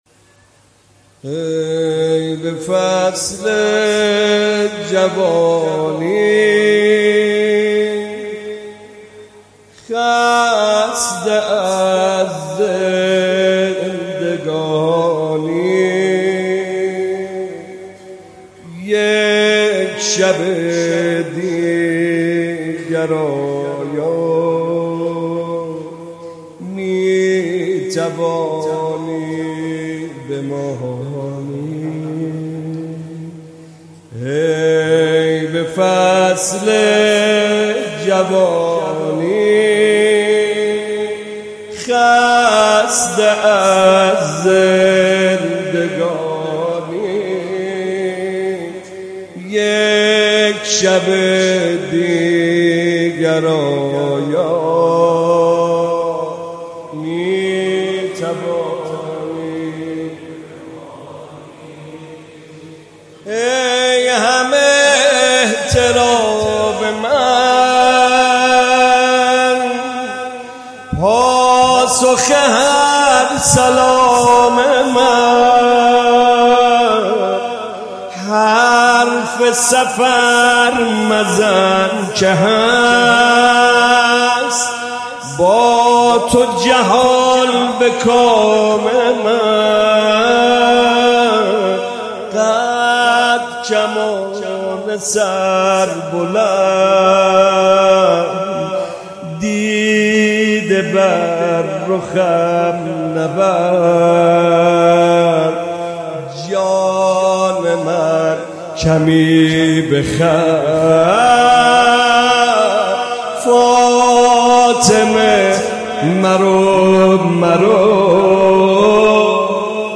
نوحه فاطمیه